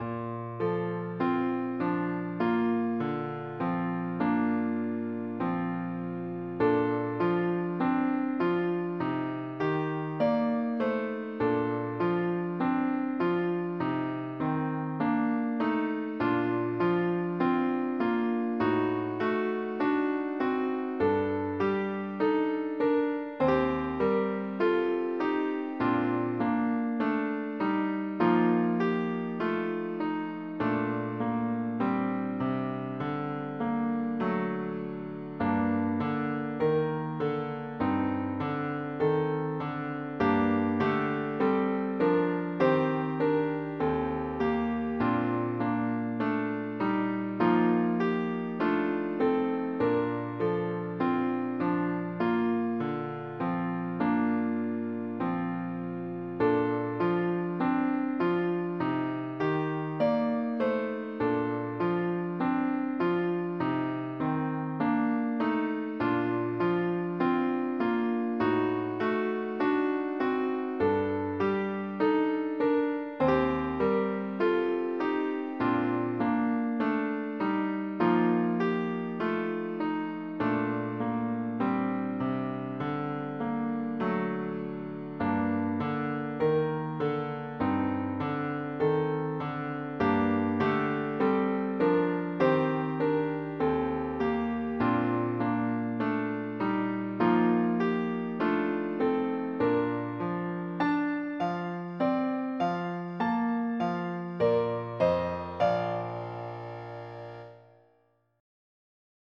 Primary Children/Primary Solo